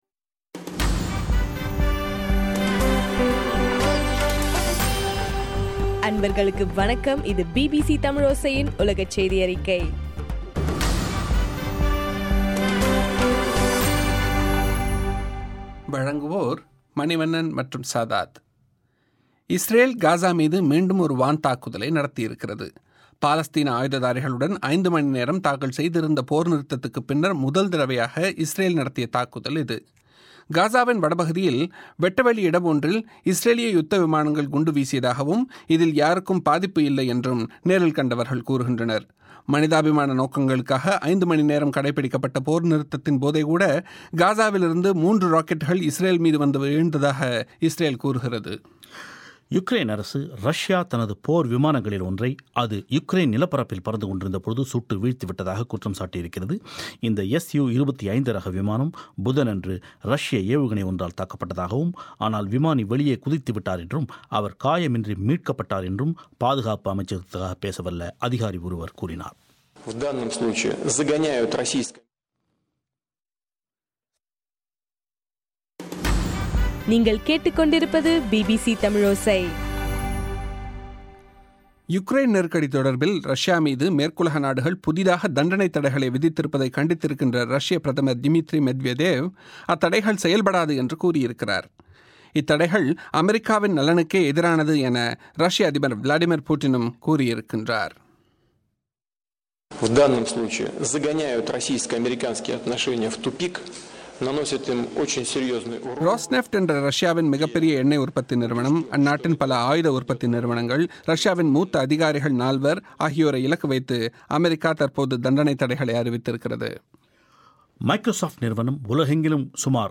இன்றைய (ஜூலை 17) பிபிசி தமிழோசை செய்தியறிக்கை